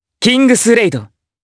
Lucias-Vox_Kingsraid_jp.wav